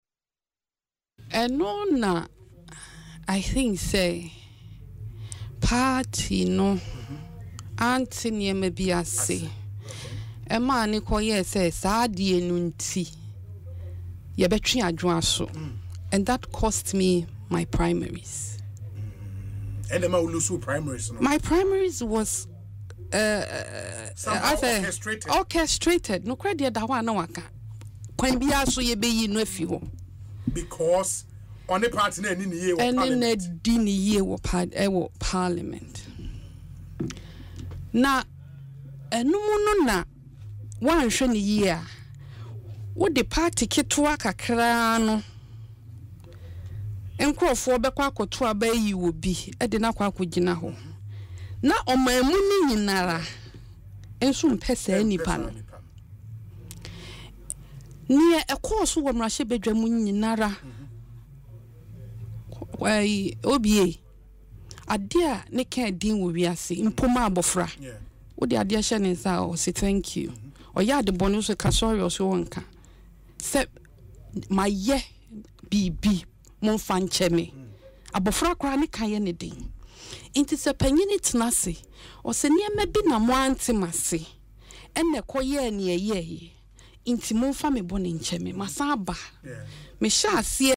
Speaking in an interview on Asempa FM’s Ekosii Sen, she stated that an impression was created that she had not been fair to the party.